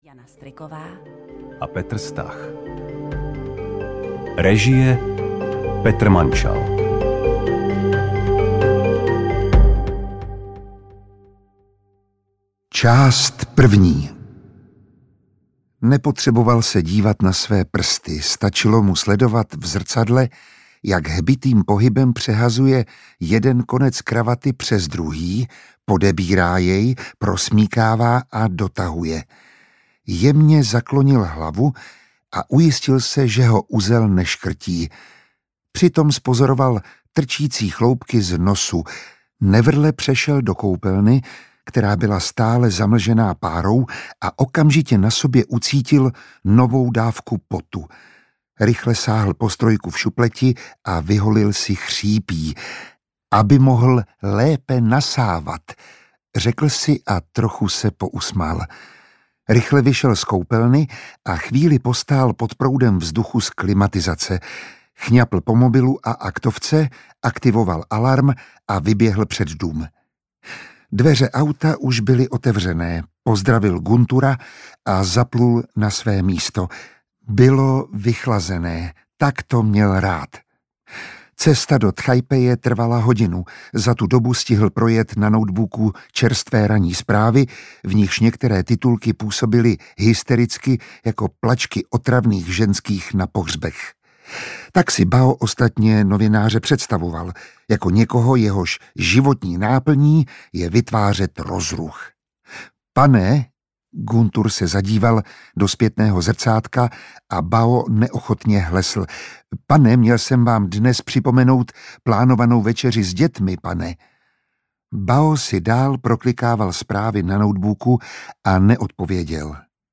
Černé jazyky audiokniha
Ukázka z knihy
• InterpretMiroslav Táborský, Jana Stryková, Petr Stach